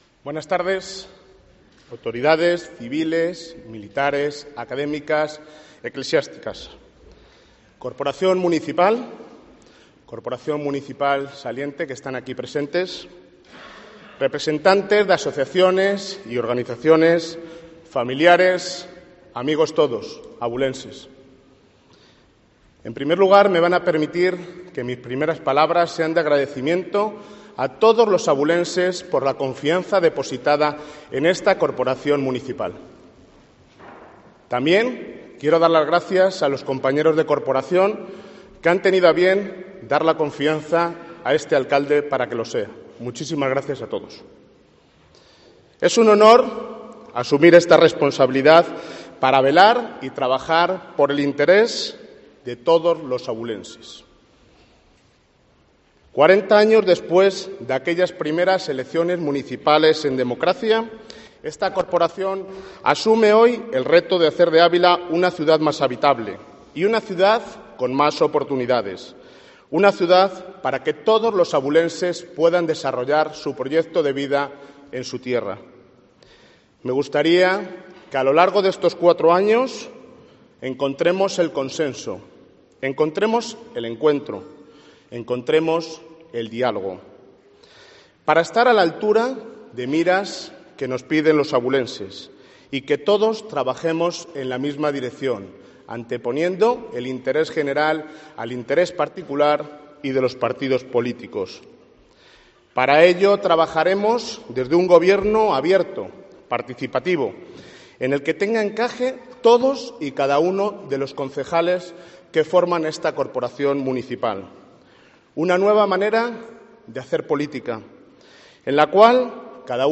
Discurso de Investidura del nuevo alcalde de Ávila, Jesús Manuel Sánchez Cabrera